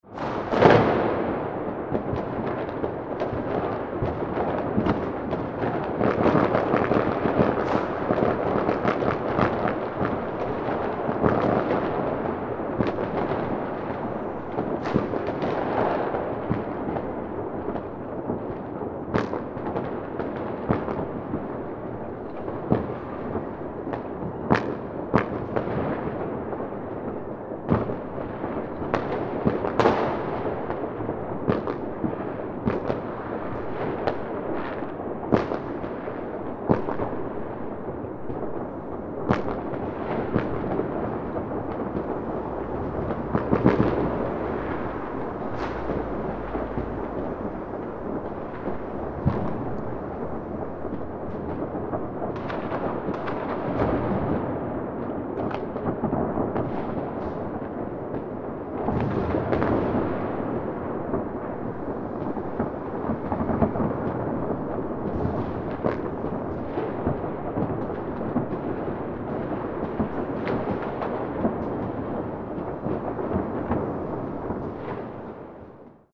Festive Fireworks And Pyrotechnics Celebration Sound Effect
Festive-fireworks-and-pyrotechnics-celebration-sound-effect.mp3